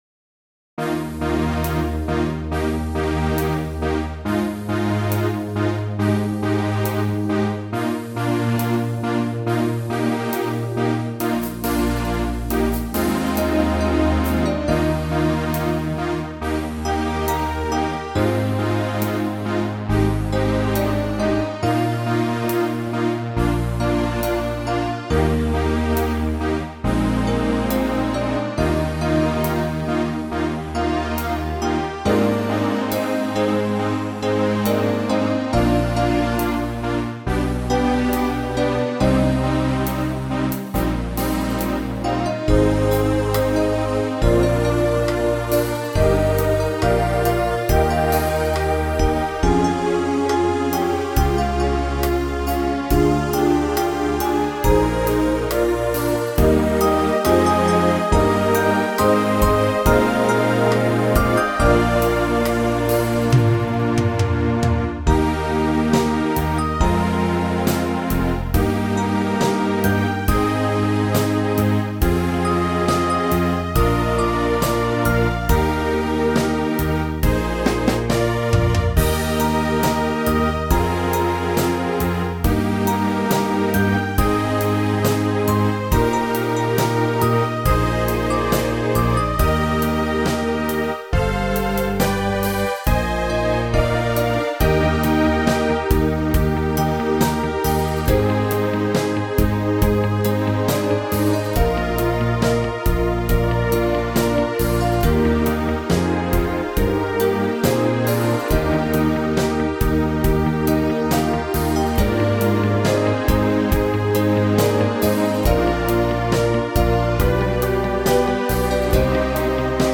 MP3 parema helipangaga salvestisest.